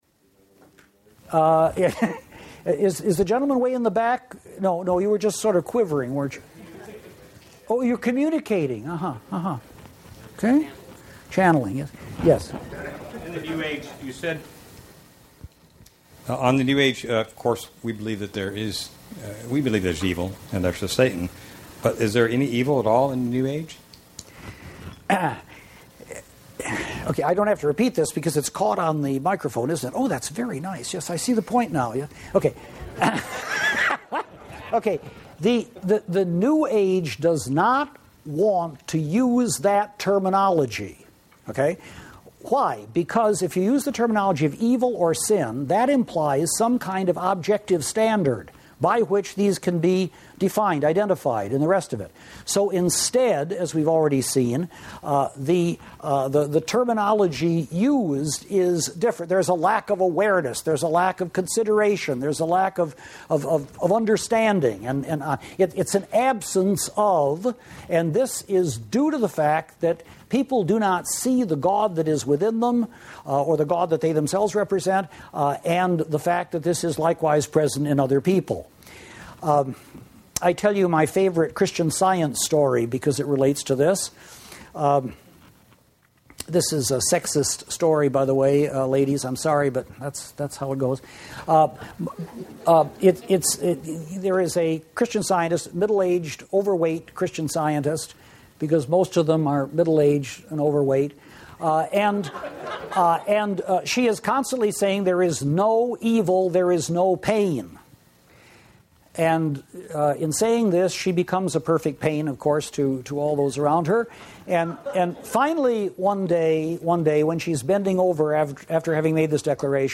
The Truth of Christianity Audiobook
10.13 Hrs. – Unabridged